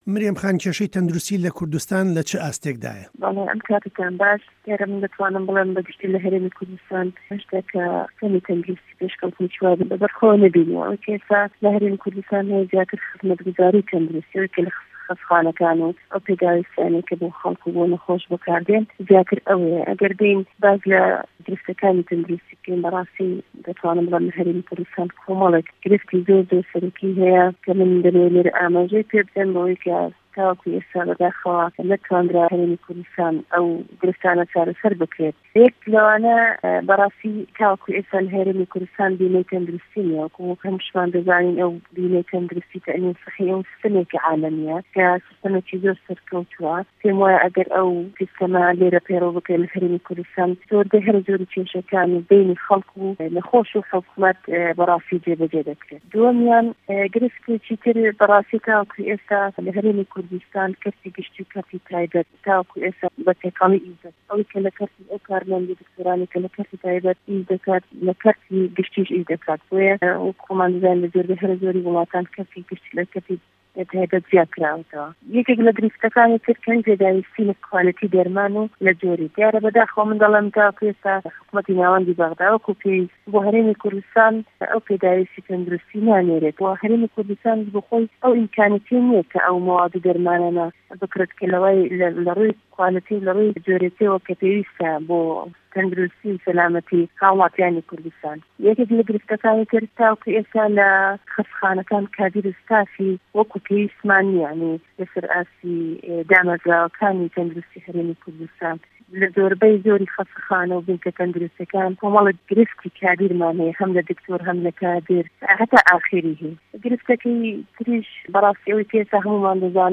له‌گفتووگۆیه‌کدا بۆ به‌شی کوردی ڕادێۆی ده‌نگی ئه‌مریکا مریه‌م سه‌مد عبدی جێگری سه‌رۆکی لیژنه‌ی ته‌ندروستی په‌رله‌مانی هه‌رێمی کوردستان ده‌ڵێت: له‌کوردستان بیمه‌ی ته‌ندروستی نیه‌،‌ که‌ سیسته‌مه‌ێکی جیهانیه‌ و سه‌رکه‌ووتوه‌، ئه‌گه‌ر له‌کوردستان په‌یڕه‌وبکرێت به‌شێکی زۆری کێشه‌کانی نێوان خه‌ڵک و حکومه‌ت چاره‌سه‌ر ده‌بێت.
گفتووگۆ له‌گه‌ڵ مریه‌م سه‌مه‌د